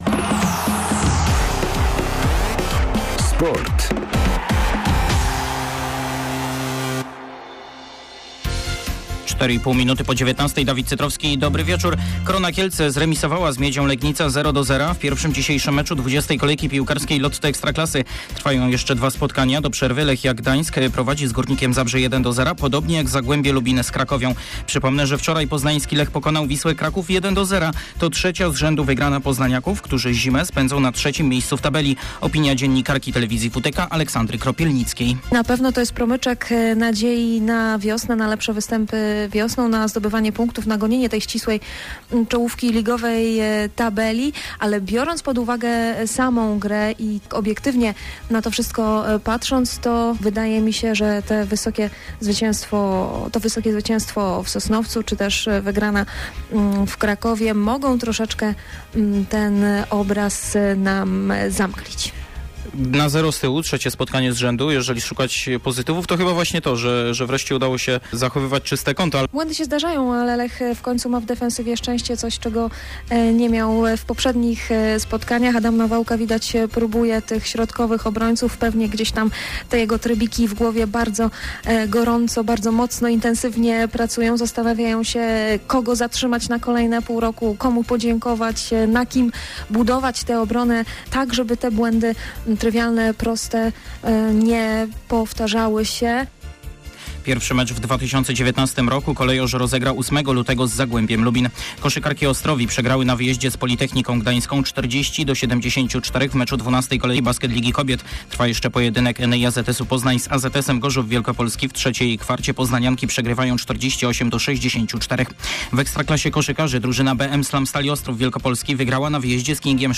22.12. serwis sportowy godz. 19:05